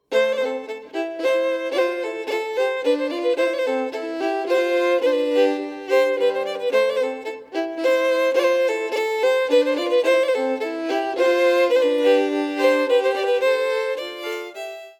3. Duo recording of tune and harmony with count in